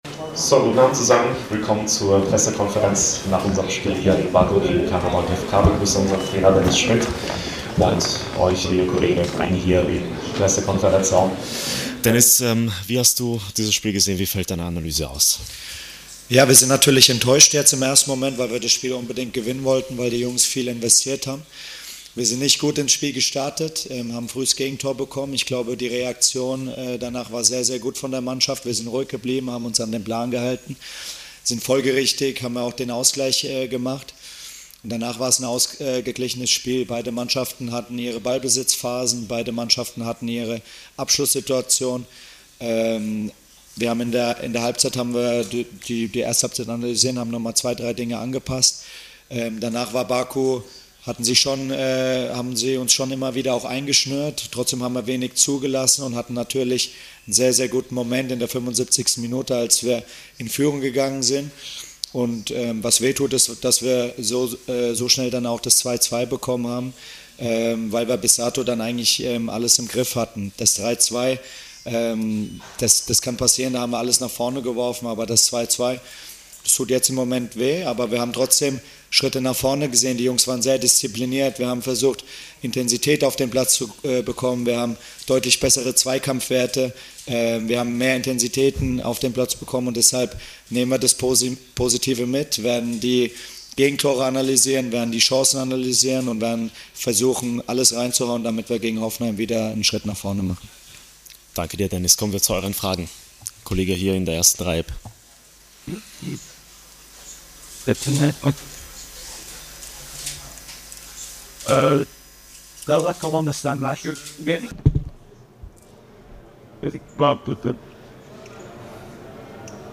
Die Pressekonferenz nach dem siebten Spieltag der UEFA Champions League